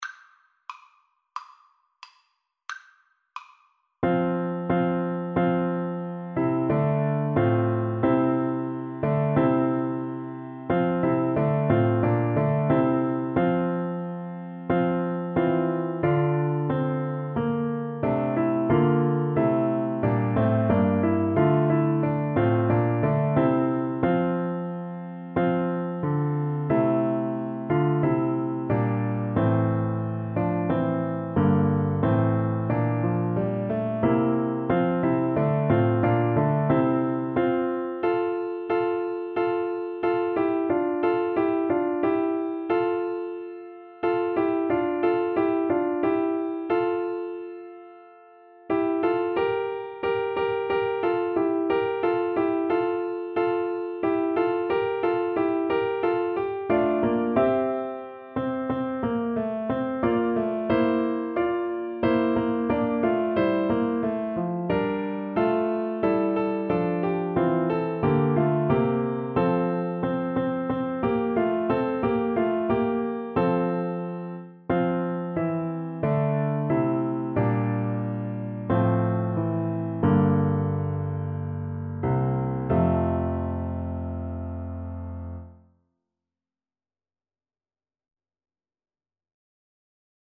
4/4 (View more 4/4 Music)
Classical (View more Classical French Horn Music)